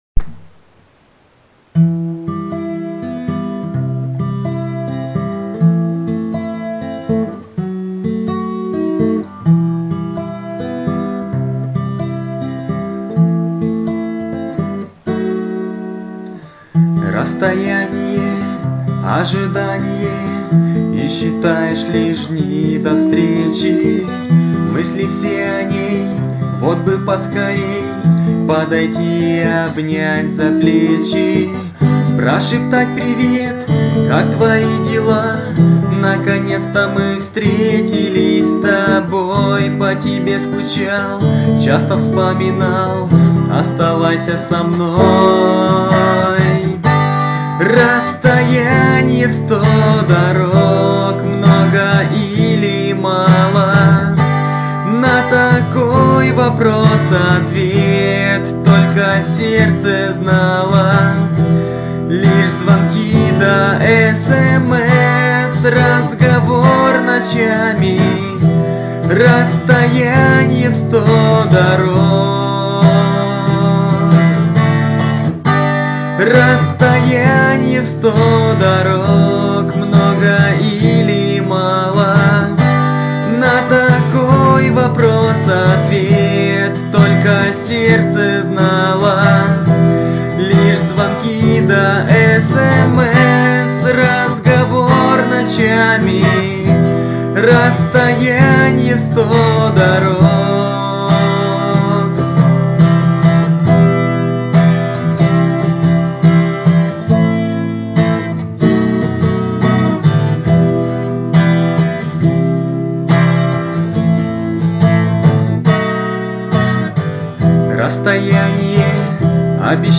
Армейские и дворовые песни под гитару
хорошая песня,мелодичная!